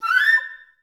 FL PKUP A5.wav